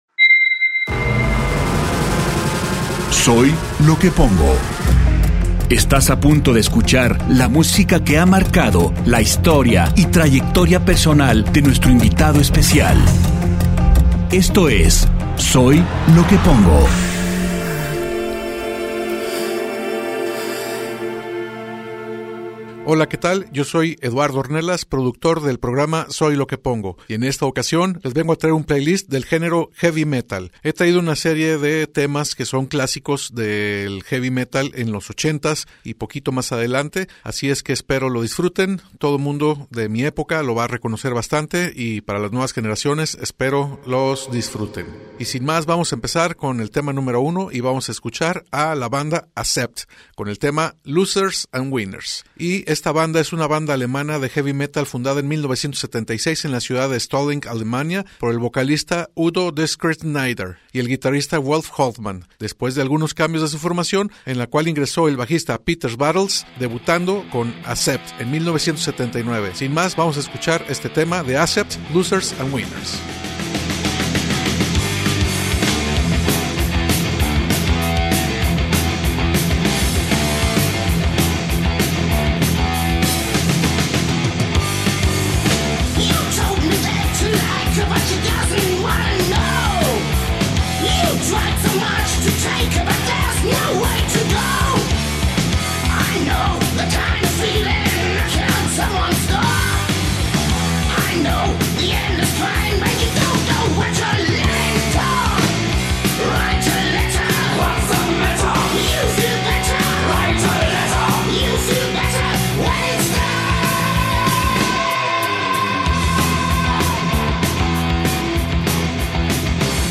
List: Heavy Metal 80s